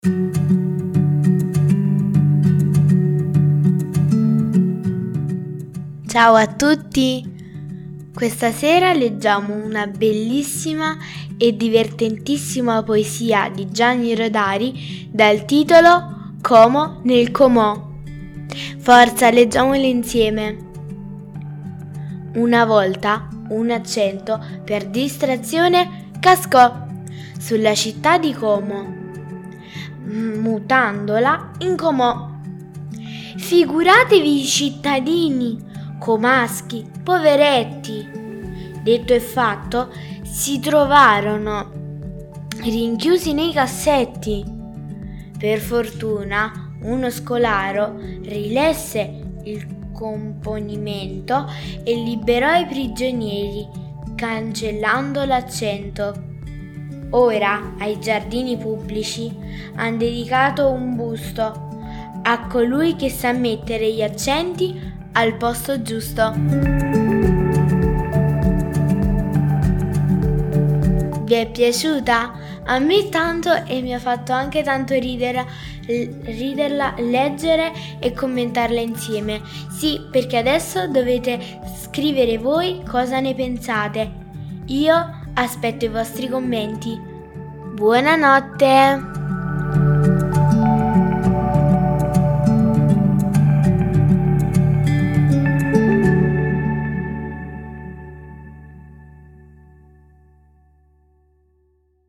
mamma legge la fiaba
Le favole della buonanotte